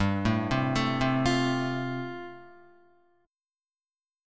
G7sus2sus4 chord